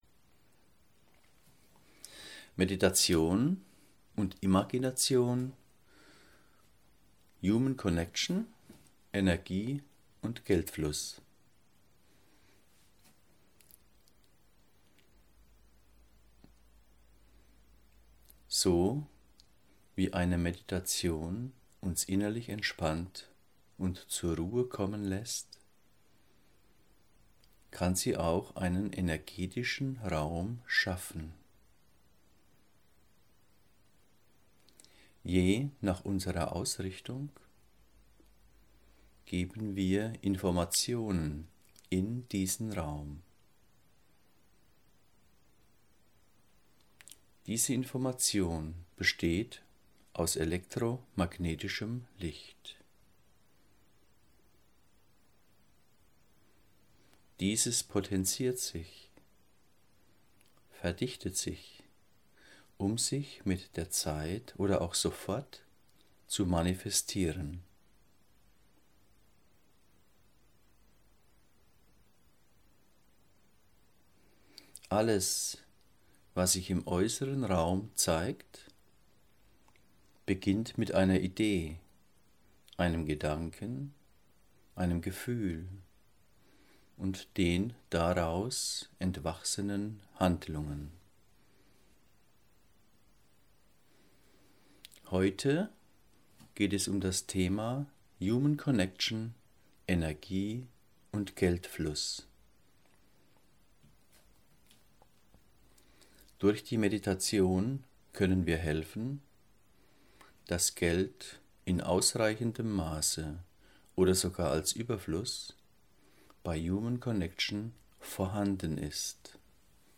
Die Meditation in voller Länge findest du hier: